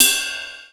072 - Ride-5.wav